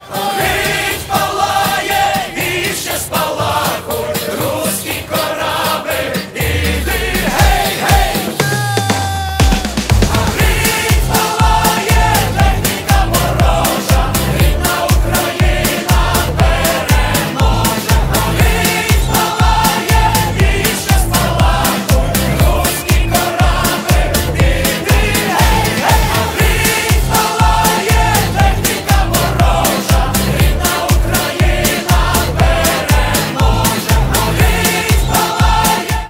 Танцевальные рингтоны
хор